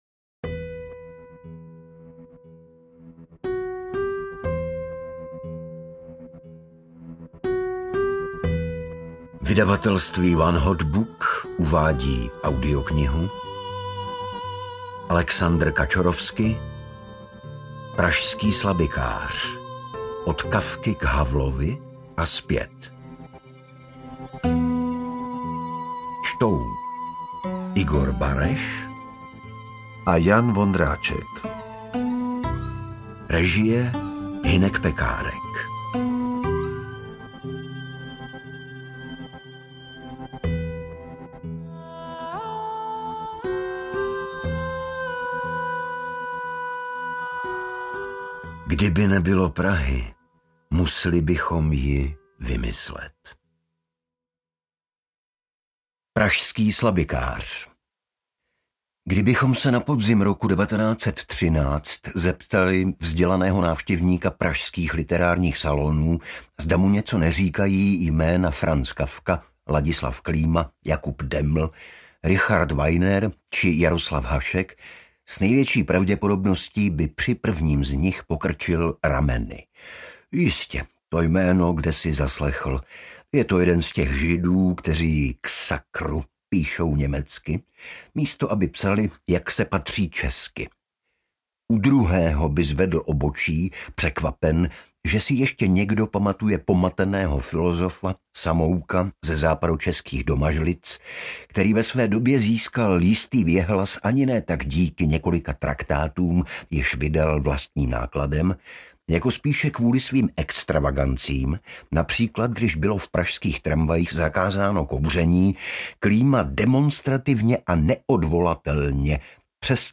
Interpreti:  Igor Bareš, Jan Vondráček
AudioKniha ke stažení, 23 x mp3, délka 8 hod. 11 min., velikost 443,0 MB, česky